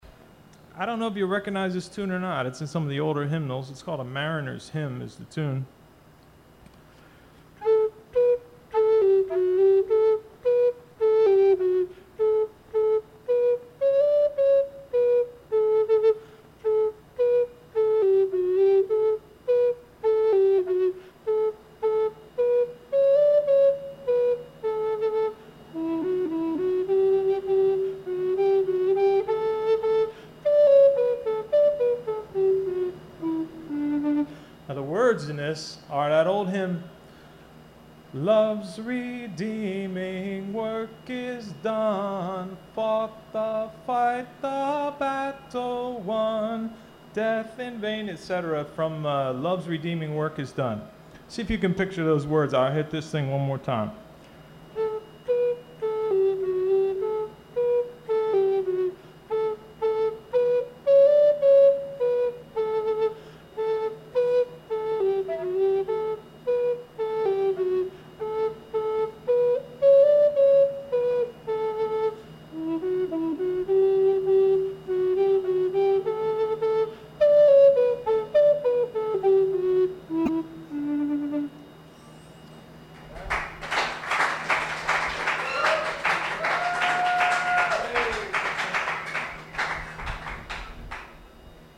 Tenor Recorder Solo- Mission Teens Christian Drug Rehab Center, Norma, NJ